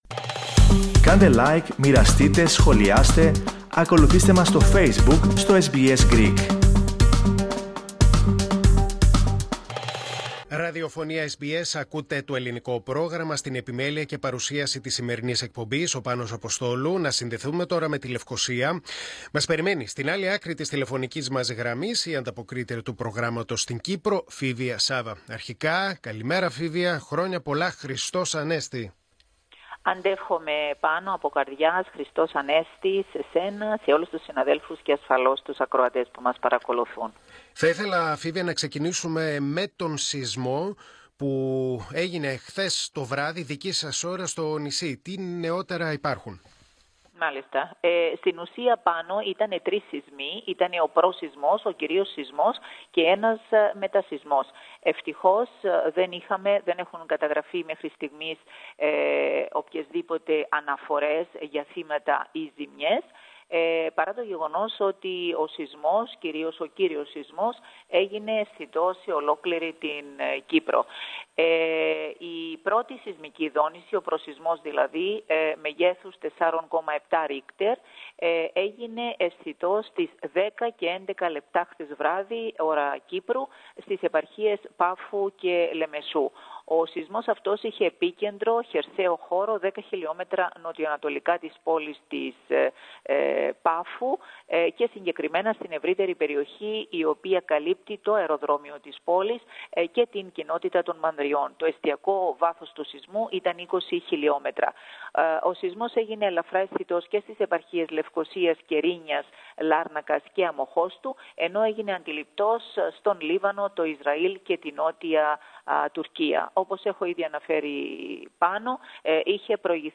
Ο σεισμός έγινε αισθητός σε Πάφο, Λεμεσό και Λευκωσία. Ακούστε ολόκληρη την ανταπόκριση από την Κύπρο, πατώντας το σύμβολο στο μέσο της κεντρικής φωτογραφίας.